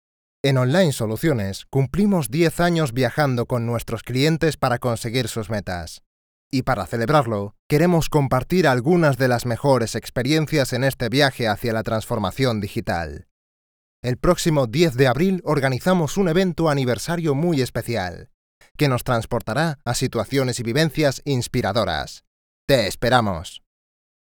Deep voice, spanish speaker, young voice
kastilisch
Sprechprobe: Sonstiges (Muttersprache):
I have a very remarkable and unique voice, with which I can change several types of records depending on the project, adapting perfectly to each of these.